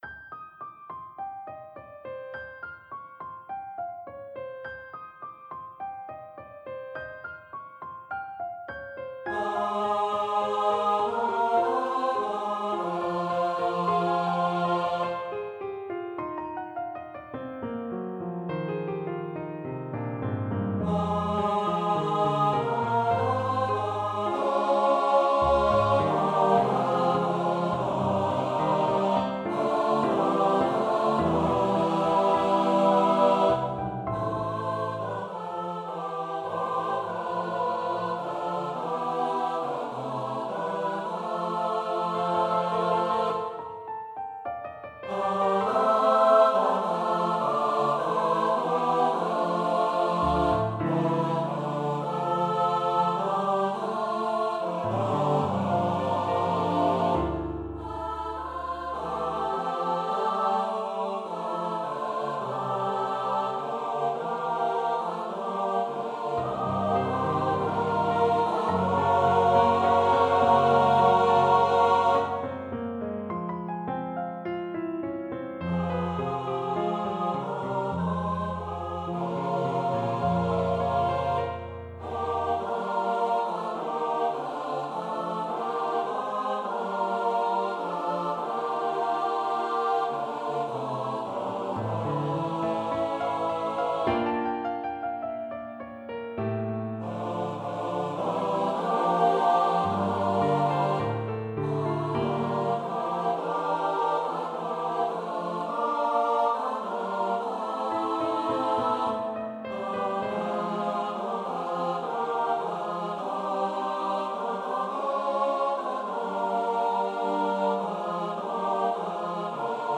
for SATB and Piano